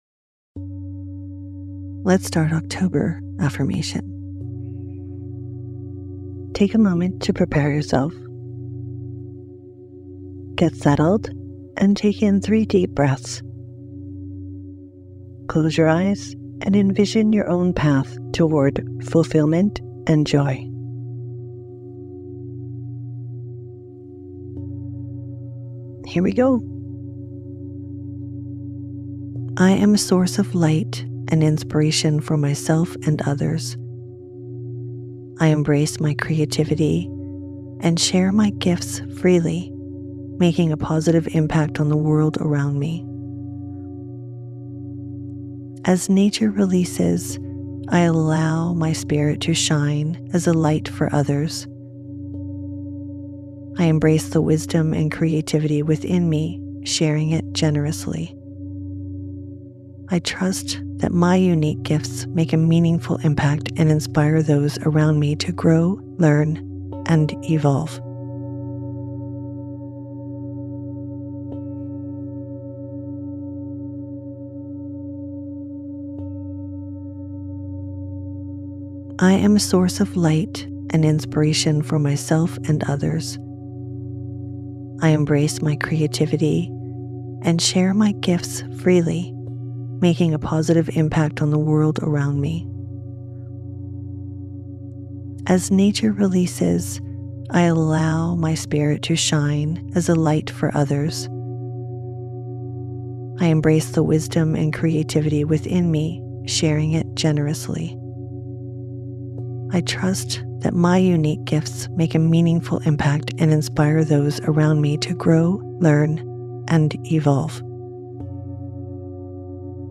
Sleep versions feature the affirmation repeated three times, creating repetition for deeper impact and greater benefits.